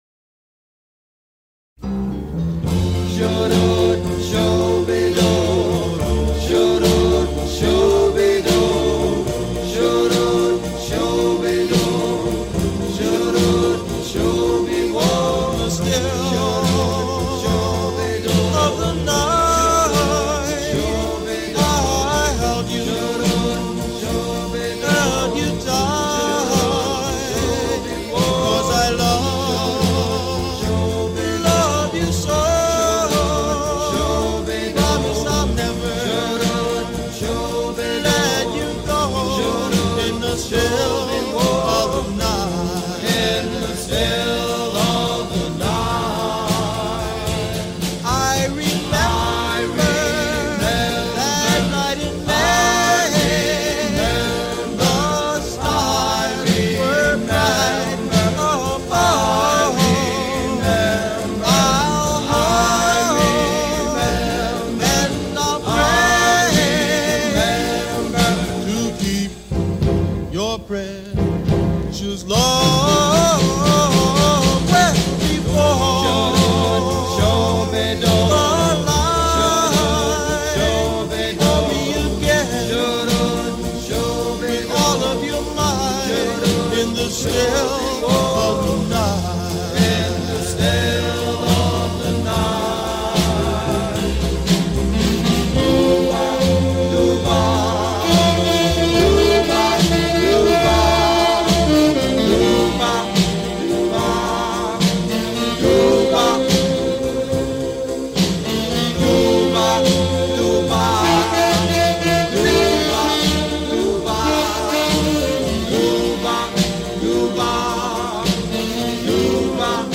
Soundtrack, Jazz, Rock